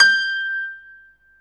SG1 PNO  G 5.wav